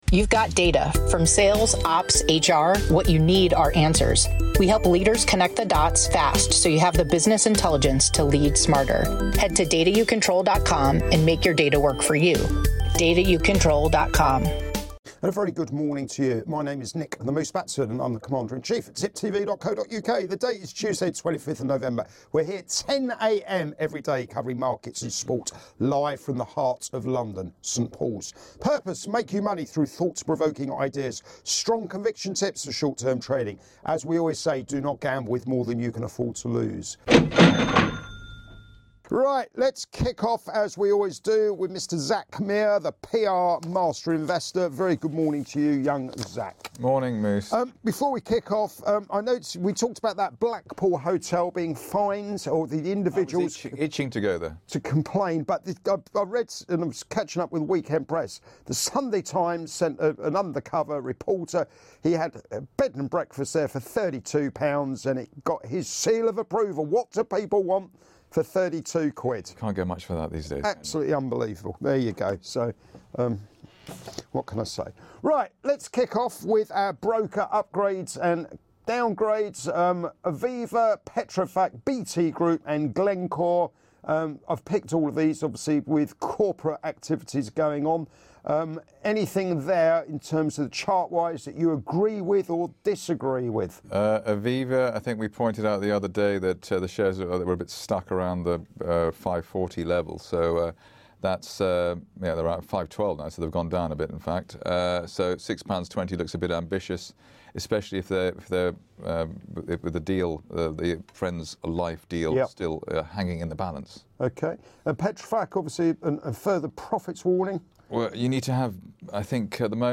Live Market Round-Up